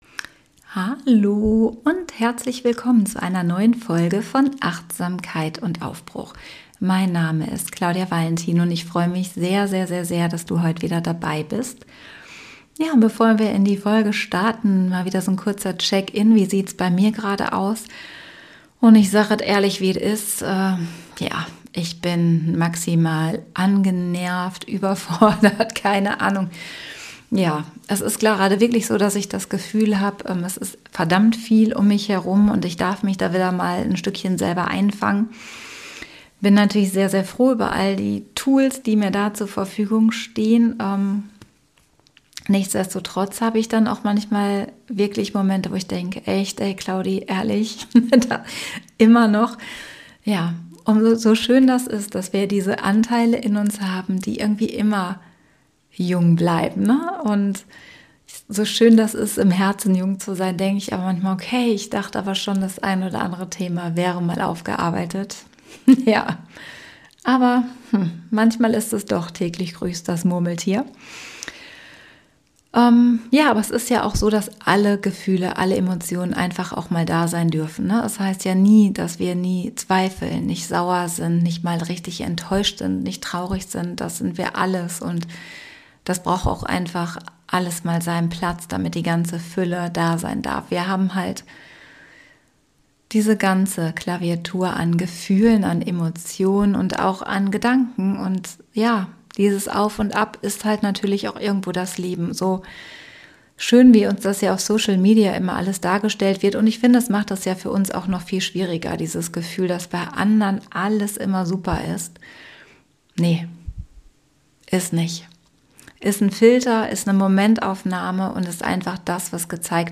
Diese Folge ist wie ein Gespräch unter Freundinnen auf der Couch: ehrlich, warm und liebevoll.